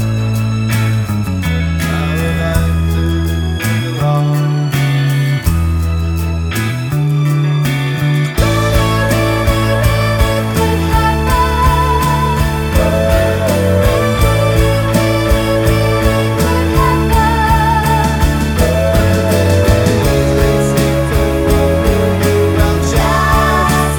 Indie / Alternative